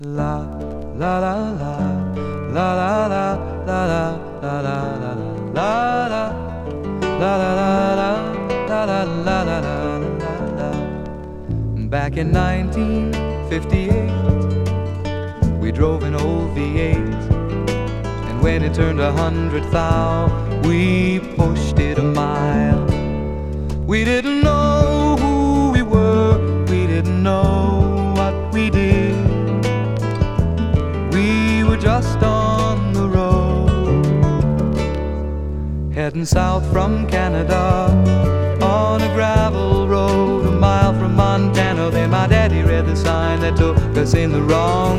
Folk, Rock, Pop, SSW　USA　12inchレコード　33rpm　Stereo